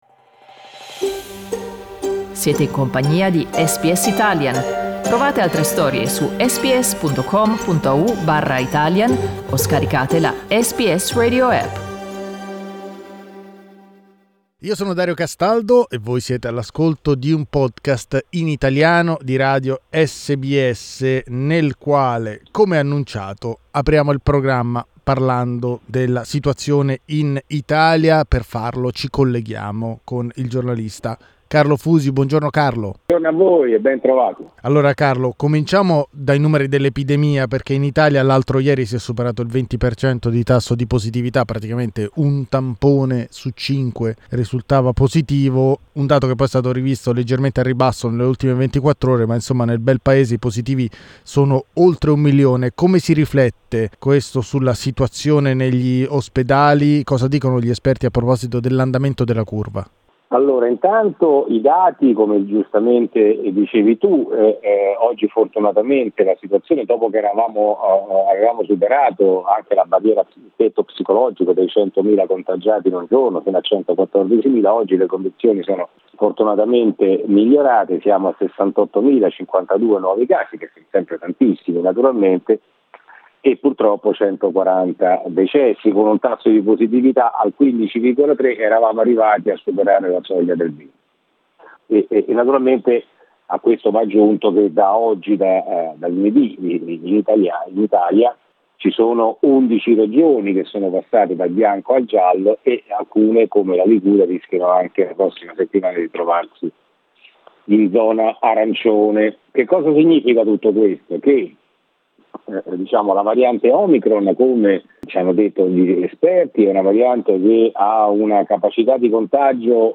Riascolta l'intervento del giornalista